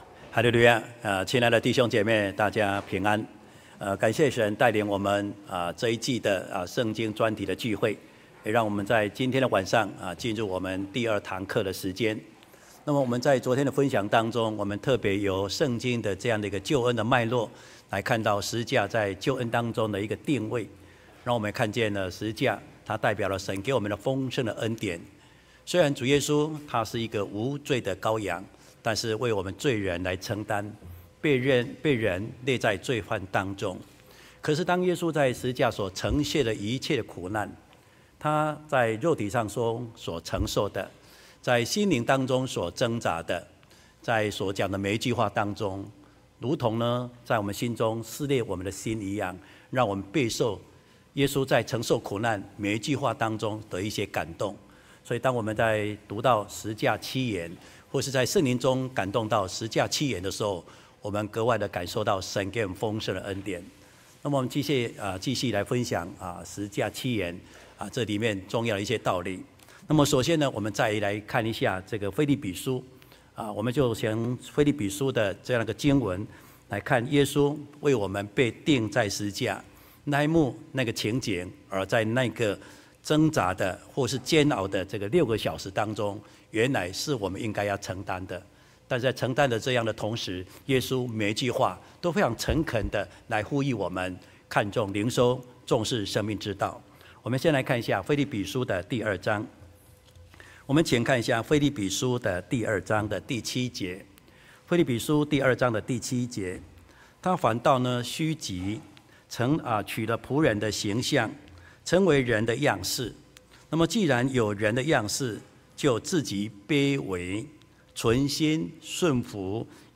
專題聚會：十架七言（二）-講道錄音